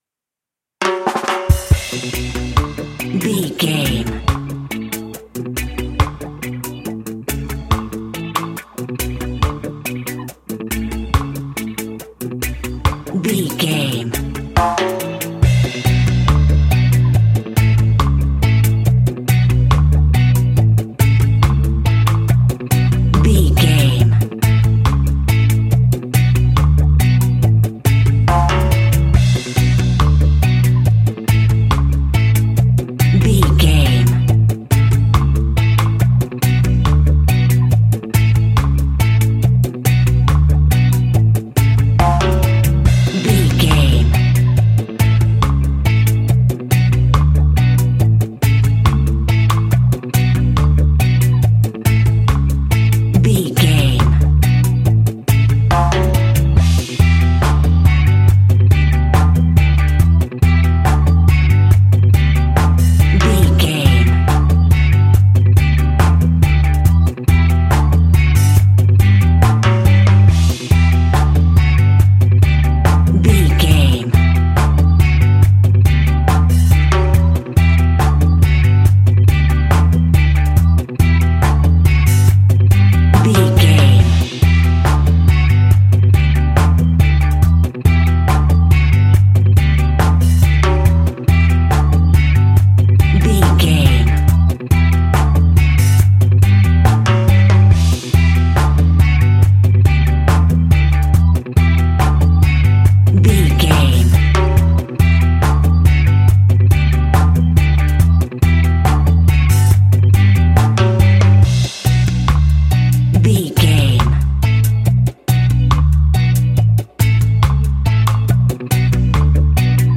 Classic reggae music with that skank bounce reggae feeling.
Aeolian/Minor
laid back
chilled
off beat
drums
skank guitar
hammond organ
percussion
horns